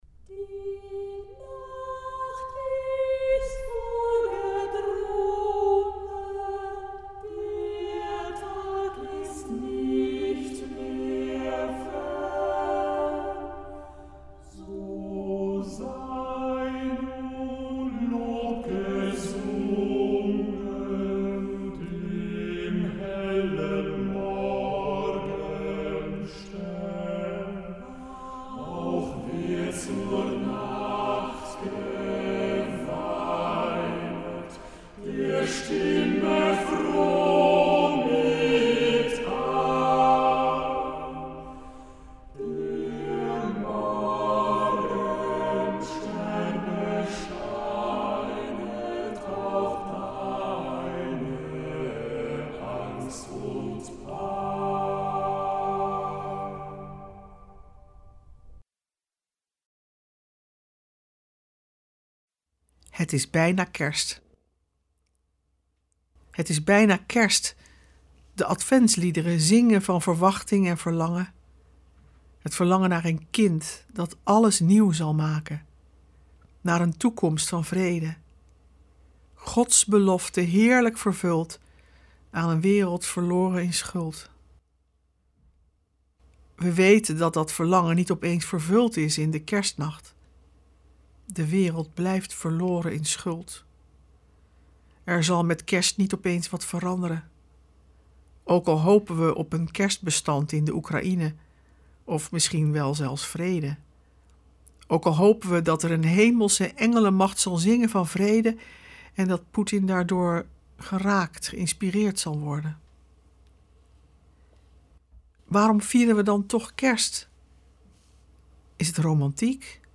We verbinden ze met onze tijd en we luisteren naar prachtige muziek en poëzie.